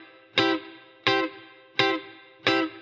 DD_TeleChop_85-Cmaj.wav